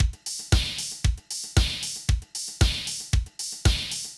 MOO Beat - Mix 1.wav